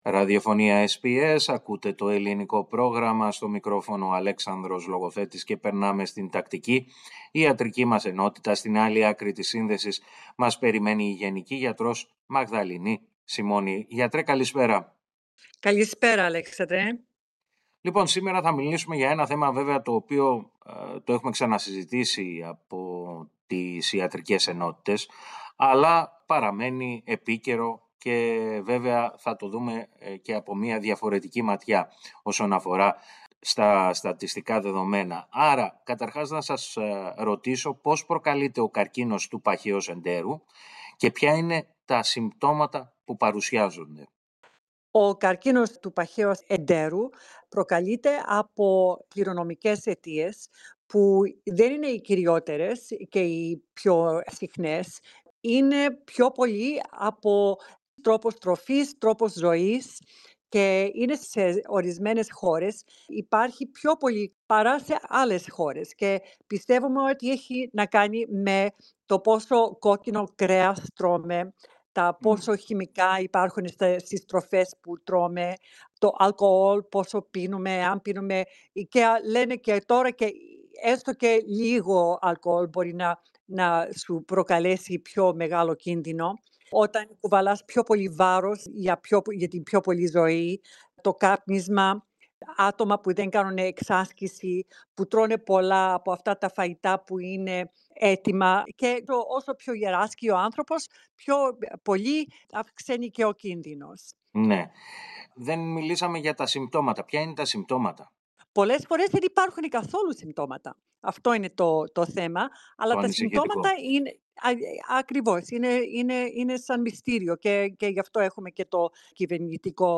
Η γενική γιατρός